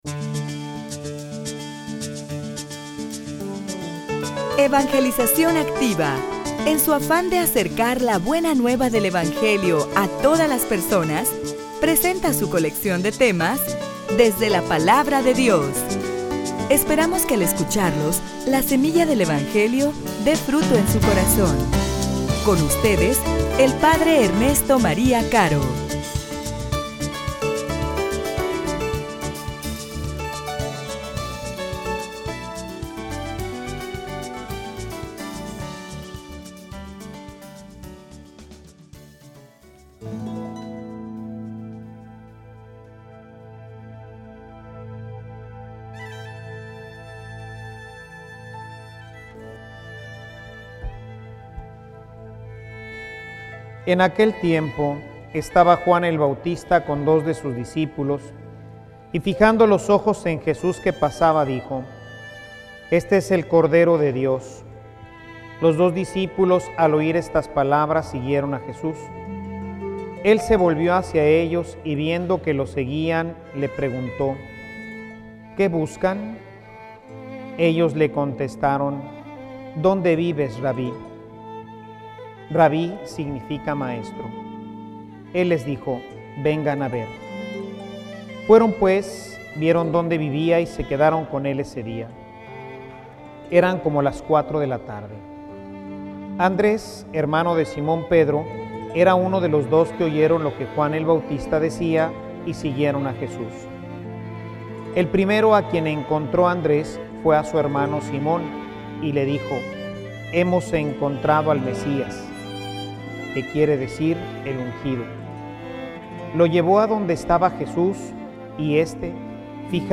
homilia_La_mejor_obra_de_toda_tu_vida.mp3